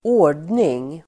Uttal: [²'å:r_dning]